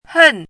怎么读
hèn
hen4.mp3